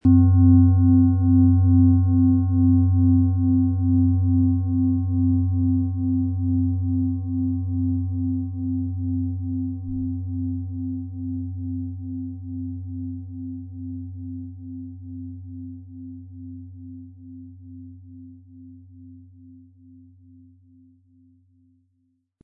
Handgefertigte Jupiter Klangschale
Ihr tiefer und resonanzreicher Klang entfaltet sich in einem großzügigen Durchmesser von 24 cm, der ein weitreichendes Klangspektrum ermöglicht.
Mit dem beiliegenden Klöppel wird Ihre Klangschale schöne Töne von sich geben.
PlanetentonJupiter
MaterialBronze